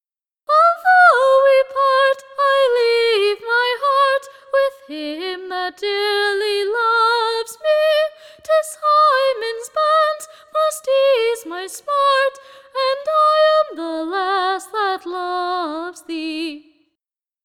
sung to “Damask Rose